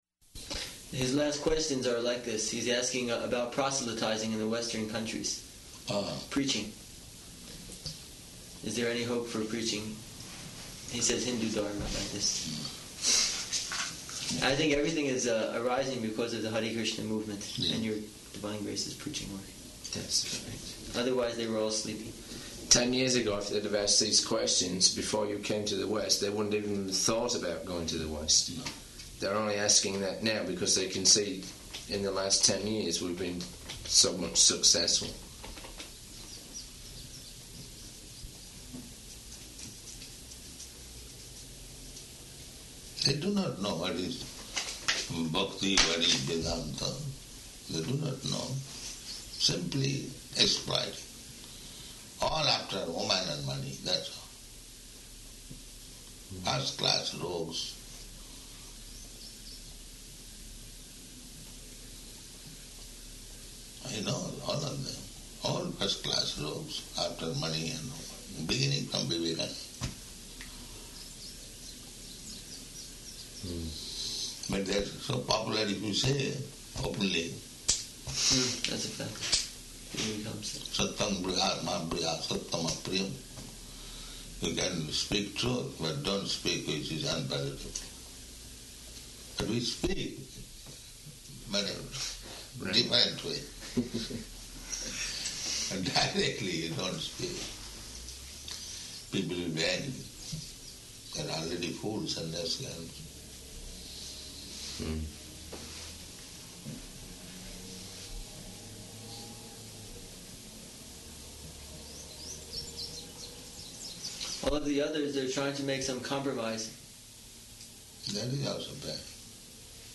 Room Conversation
Location: New Vrindavan